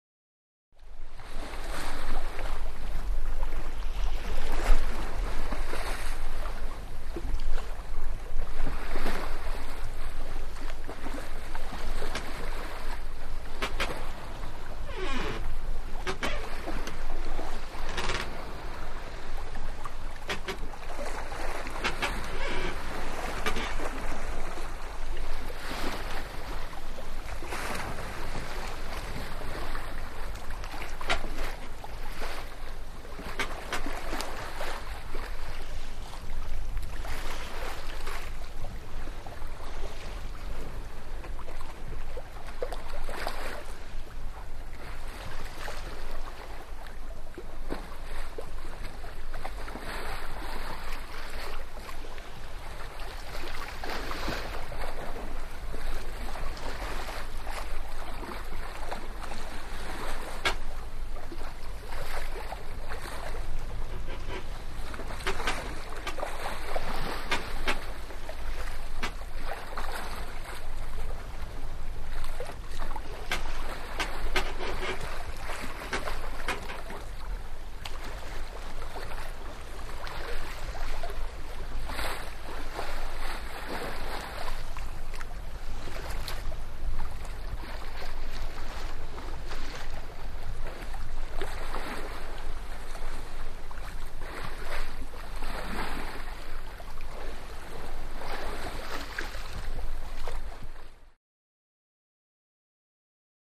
Sailing Ship; Sea Wash; Sea Wash From Deck Of Sailing Ship. Very Calm Sea With Boat Creaks And Light Blustery Wind.